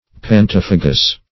Search Result for " pantophagous" : The Collaborative International Dictionary of English v.0.48: Pantophagous \Pan*toph"a*gous\, a. [Gr. pantofa`gos; pa^s, panto`s, all + fagei^n to eat.] Eating all kinds of food.